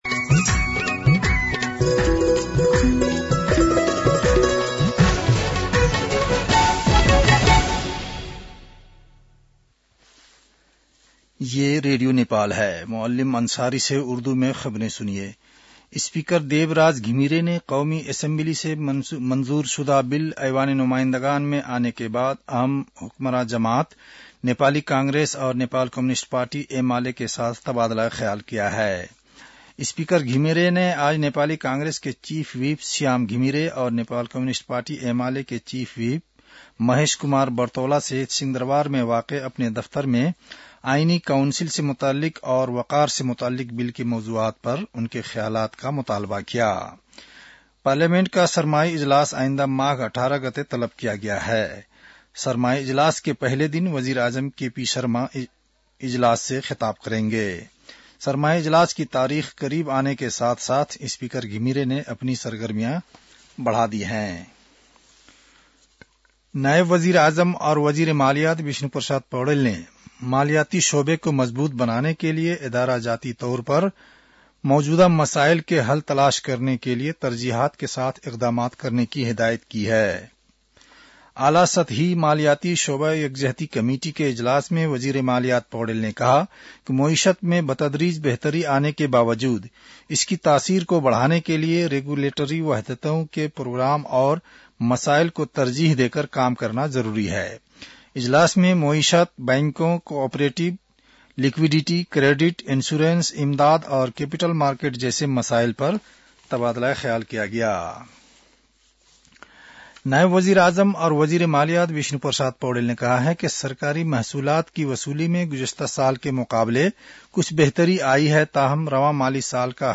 उर्दु भाषामा समाचार : १४ माघ , २०८१